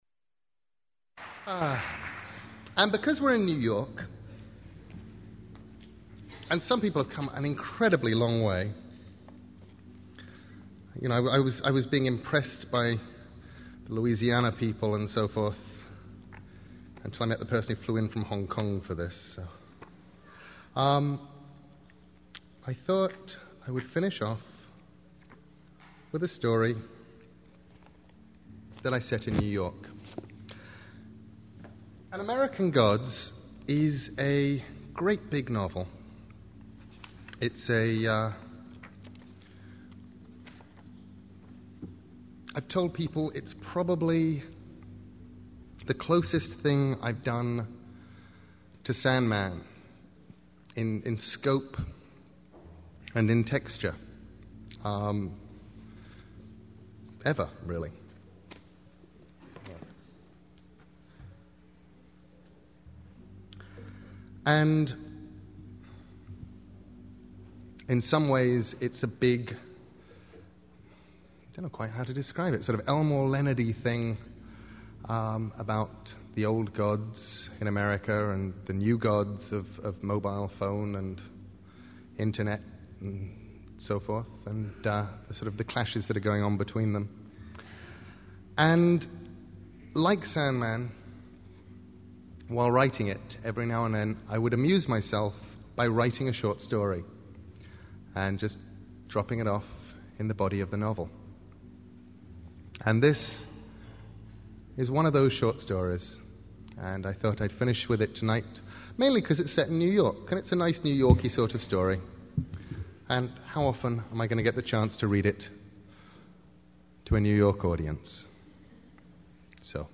MP3: Neil Gaiman HarperCollins Sales Talk - 5 MB Last Angel Tour Readings for the CBLDF Here you can listen to Neil read from American Gods on his Last Angel Tour in various cities around the U.S. These clips are courtesy of the Comic Book Legal Defense Fund, a First Amendment Defender.